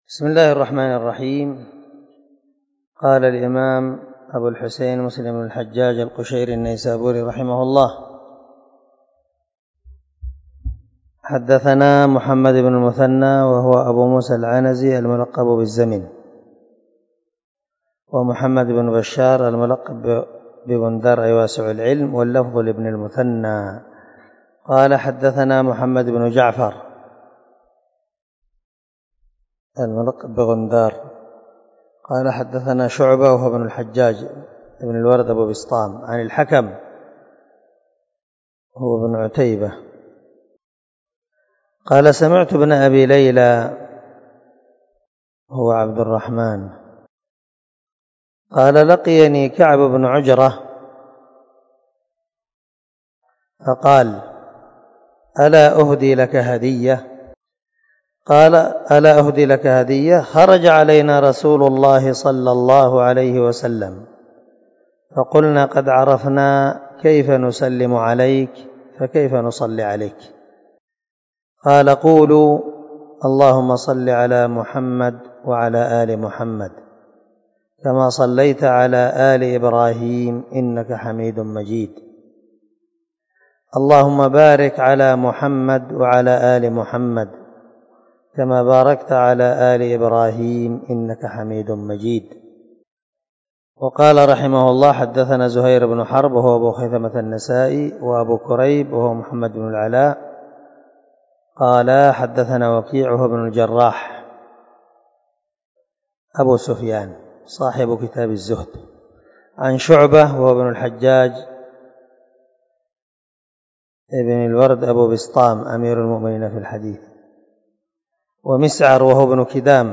282الدرس 26 من شرح كتاب الصلاة حديث رقم ( 406 – 407 ) من صحيح مسلم
دار الحديث- المَحاوِلة- الصبيحة.